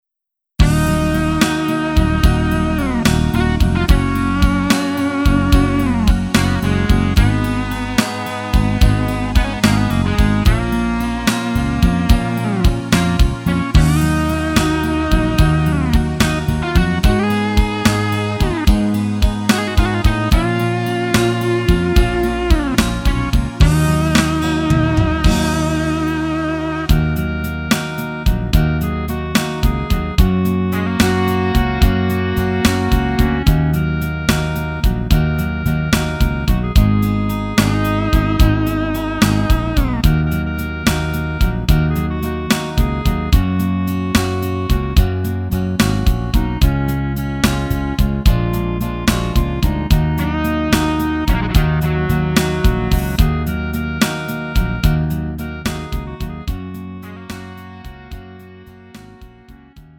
음정 -1키 3:53
장르 구분 Lite MR